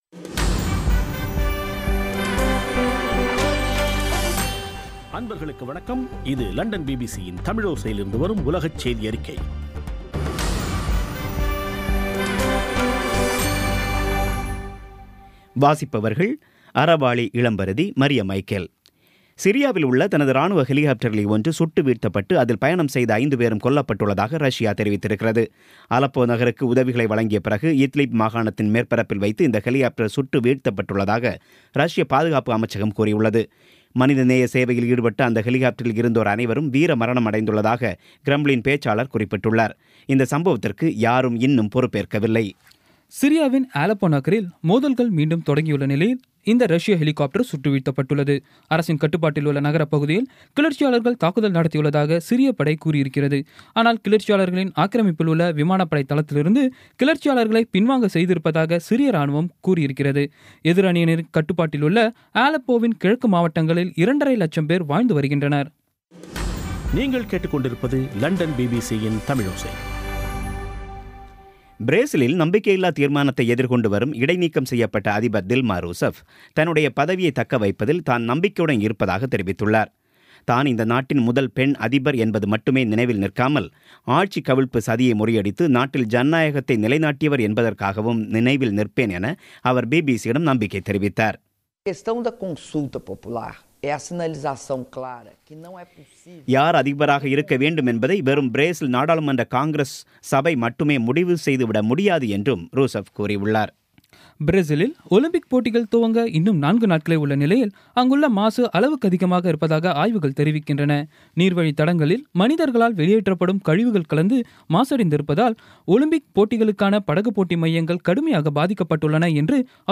பிபிசி தமிழோசை செய்தியறிக்கை (01/08/16)